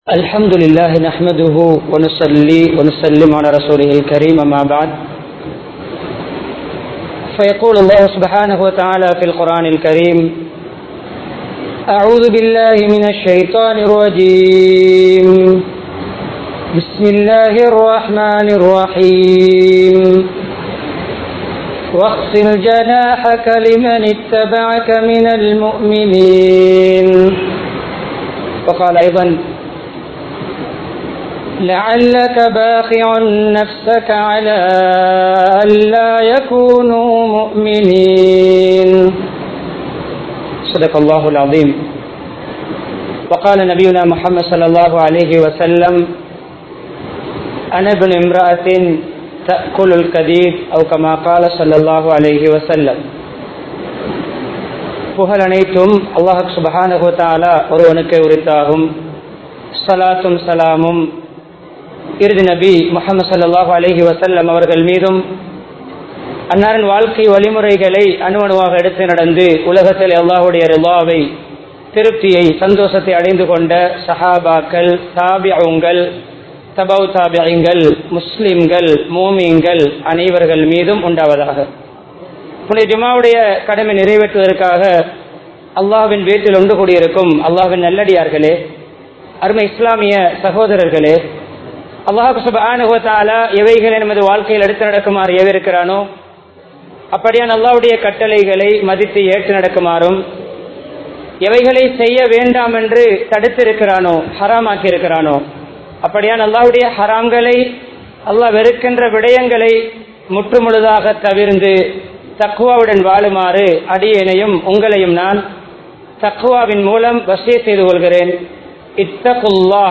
நான் என்ற அகங்காரம் (Self Arrogance) | Audio Bayans | All Ceylon Muslim Youth Community | Addalaichenai
Dehiwela, Kawdana Road Jumua Masjidh